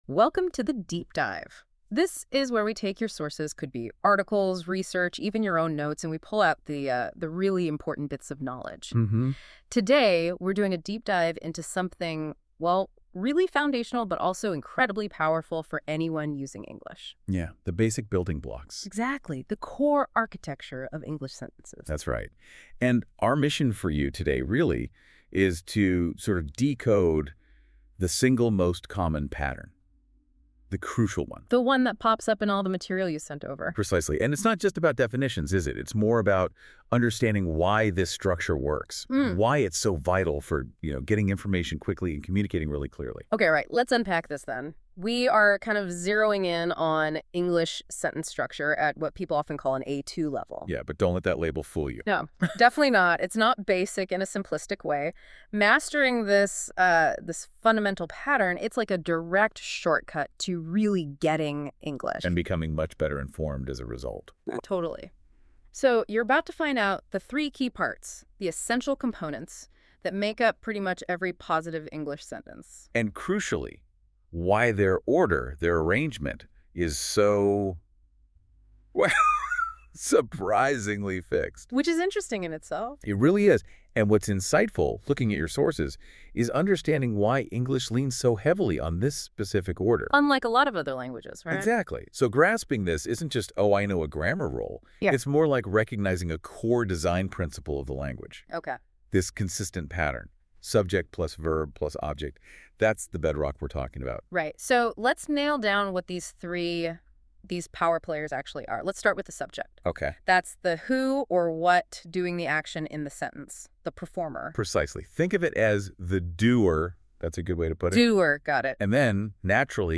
• Audio Lessons